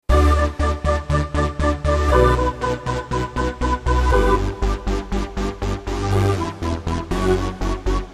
标签： 黑暗 钢琴 悬疑 恐怖 困扰 焦虑 令人毛骨悚然 邪恶 恐怖 怪异 恐怖
声道立体声